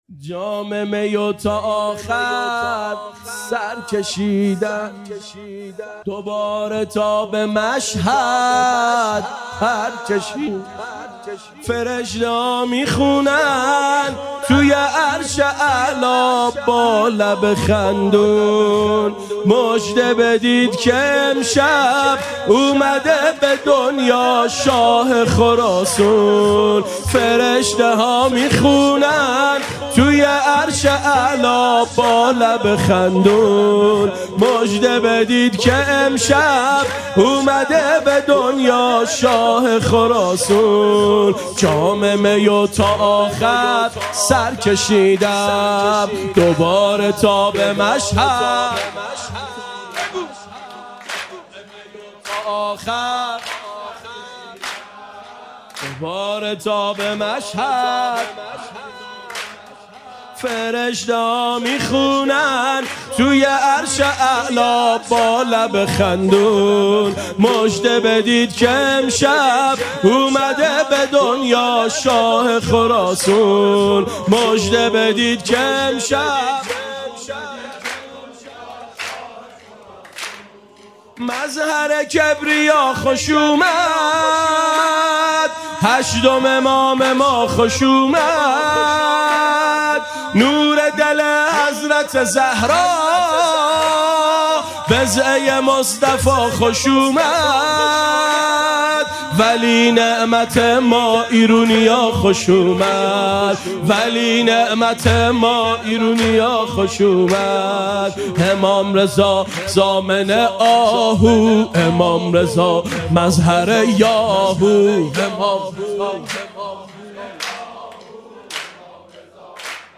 جشن میلاد امام رضا علیه السلام
سرود میلاد امام رضا علیه السلام